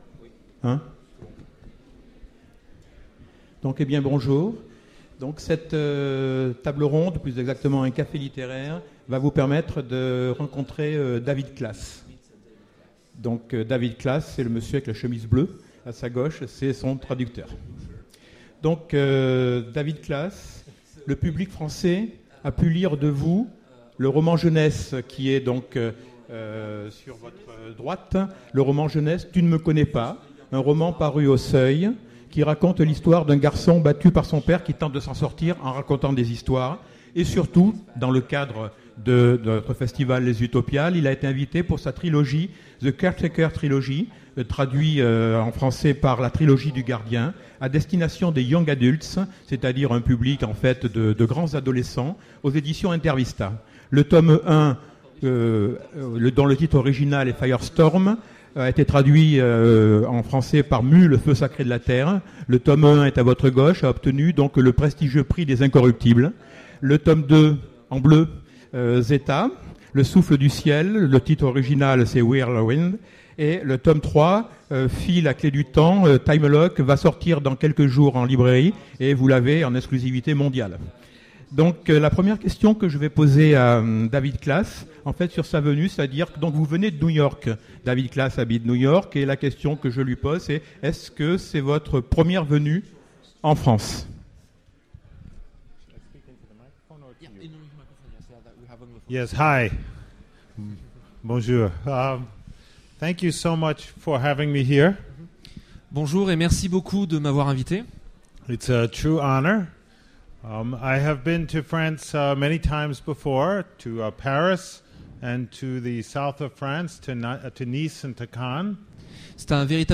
Voici l'enregistrement de la rencontre avec David Klass aux Utopiales 2009.
Télécharger le MP3 à lire aussi David Klass Genres / Mots-clés Rencontre avec un auteur Conférence Partager cet article